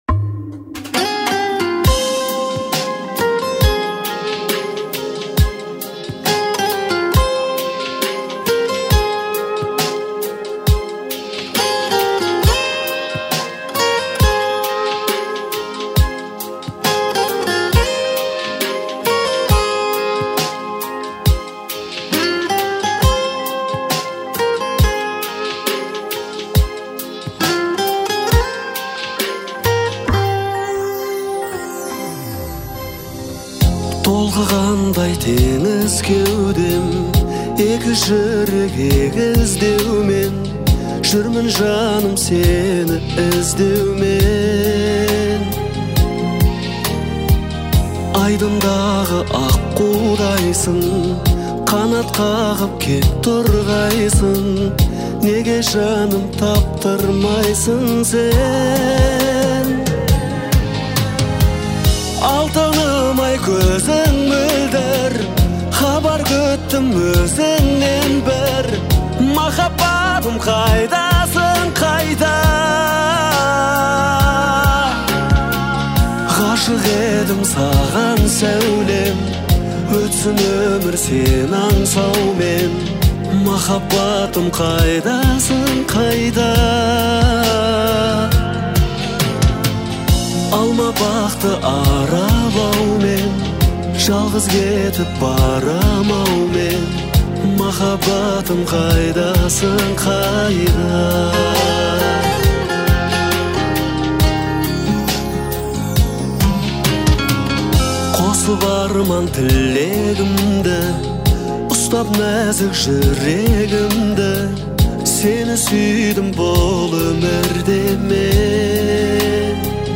это трогательная казахская песня в жанре поп-фолк